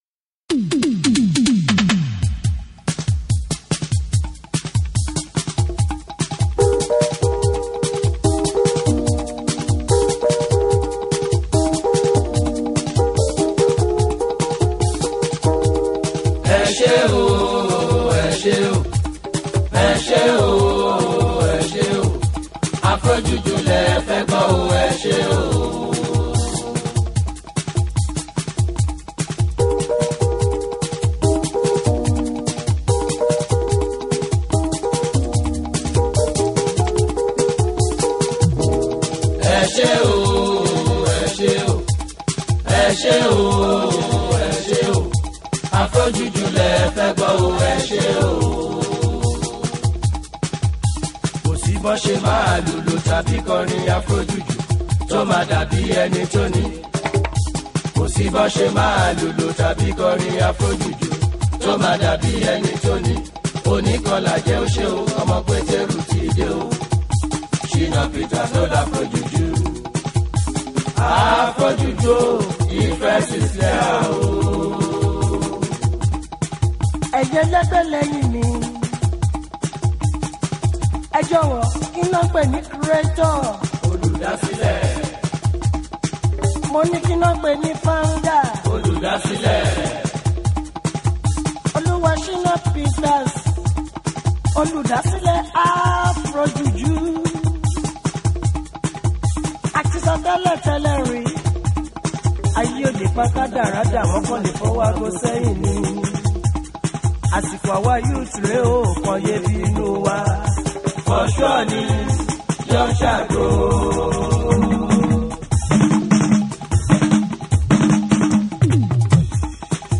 the track is sure to get you on your feet